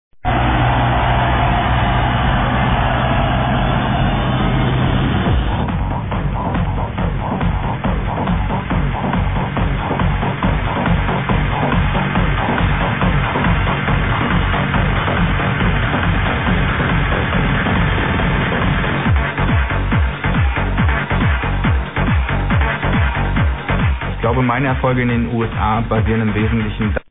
documentary
sound like a wild track